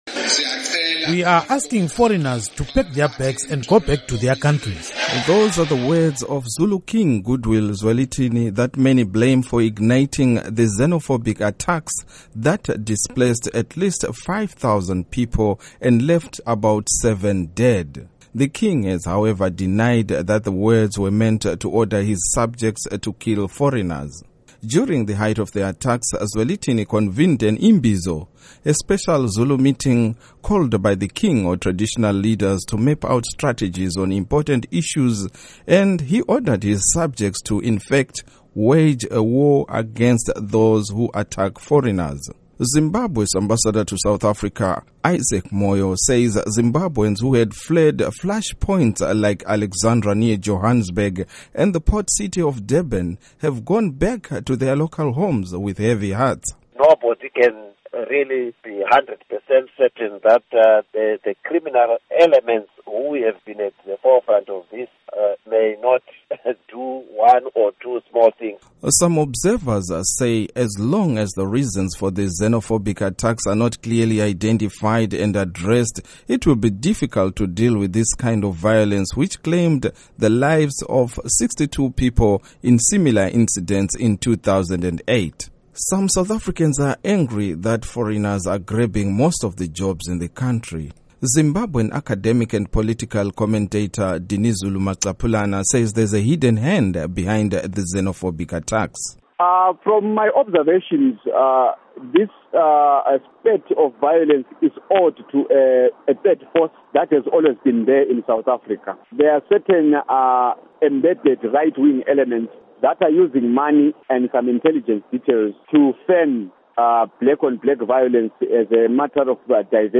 Report on Xenophobic Attacks